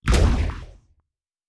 Sci Fi Explosion 08.wav